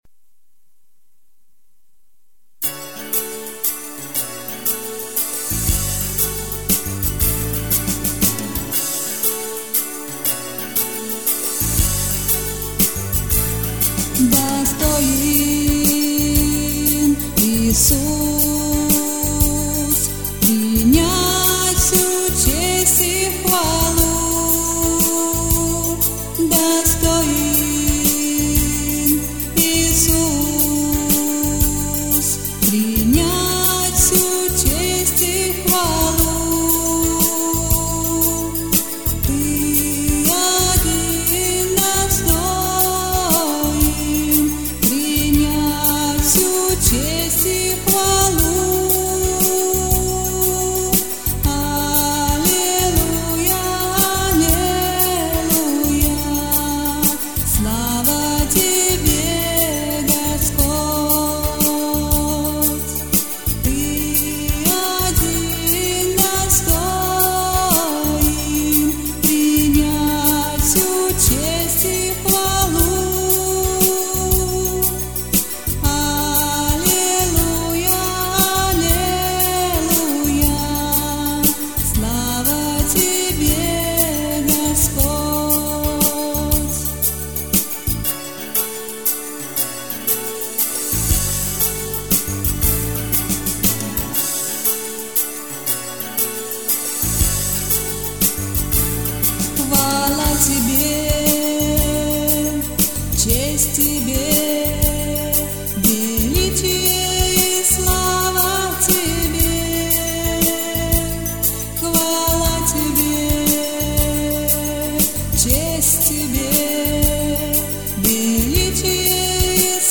334 просмотра 78 прослушиваний 9 скачиваний BPM: 118